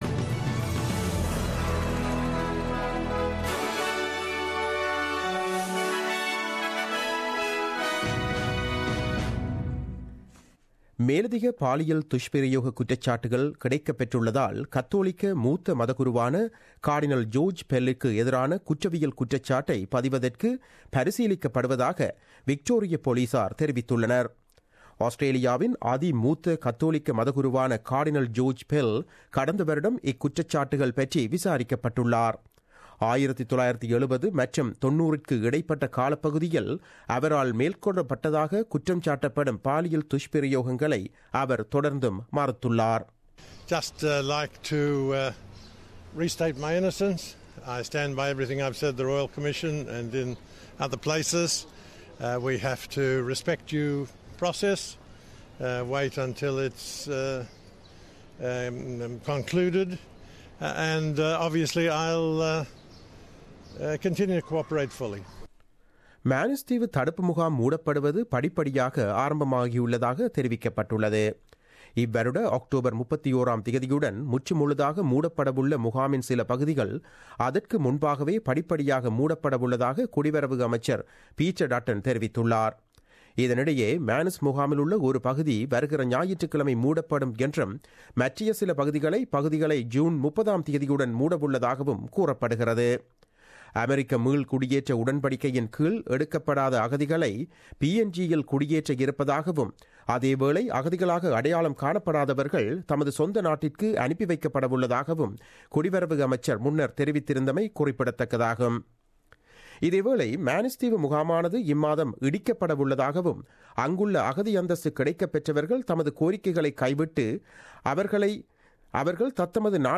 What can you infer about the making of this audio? The news bulletin aired on Wednesday 17 May 2017 at 8pm.